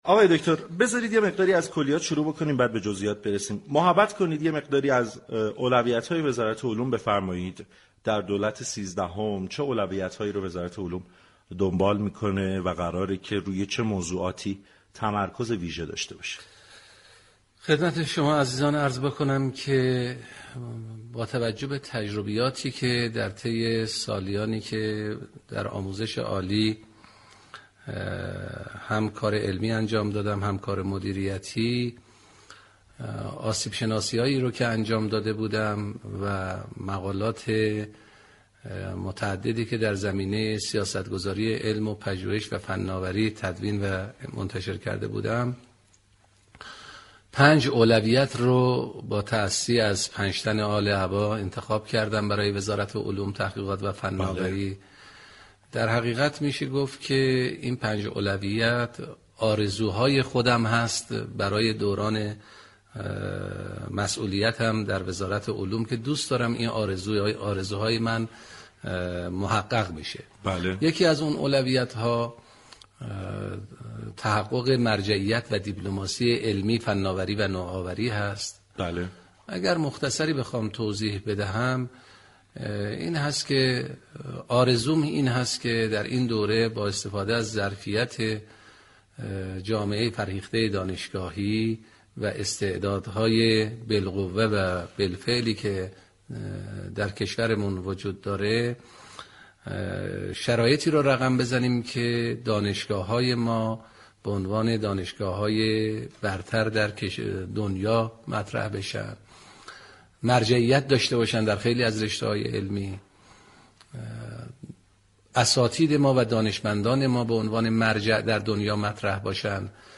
به گزارش پایگاه اطلاع رسانی رادیو تهران، وزیر علوم، تحقیقات و فناوری ساعتی بعد از برگزاری پنجاه و دومین كنكور سراسری كشور با حضور در استودیو پخش زنده رادیو تهران با برنامه سعادت آباد گفت و گو كرد.